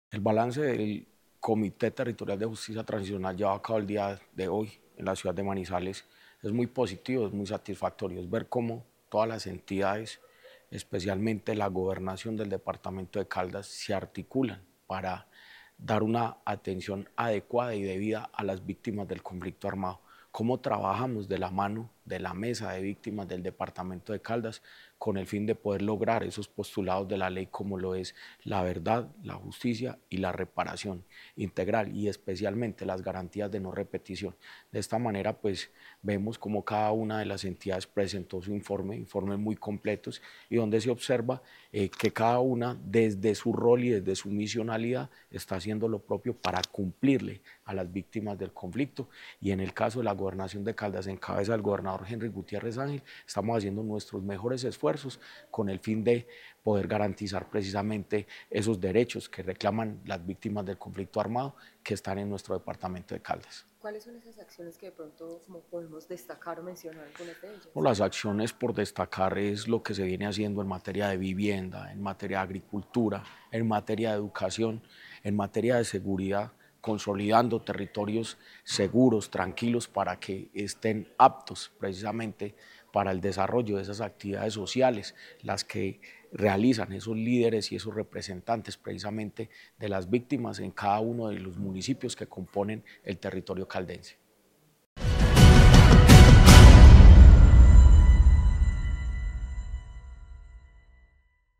Descargar Video Descargar Audio  Jorge Andrés Gómez Escudero, secretario de Gobierno de Caldas.